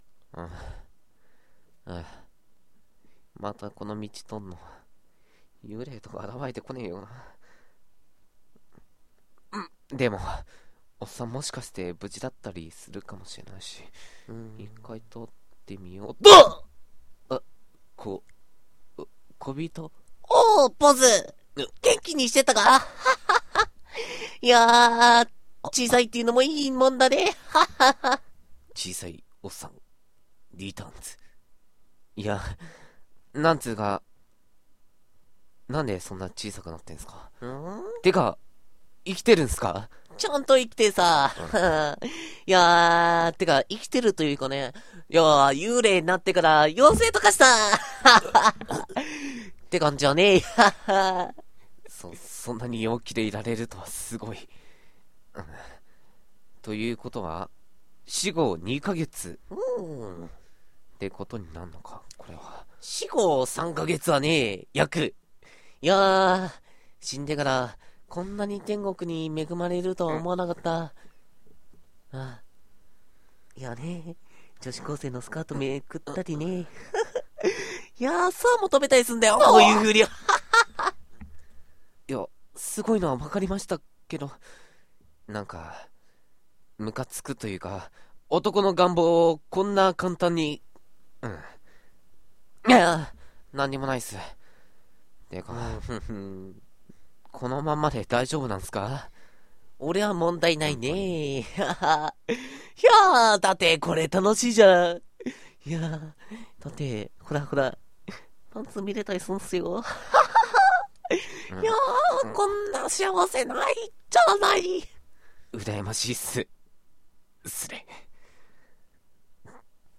1人2役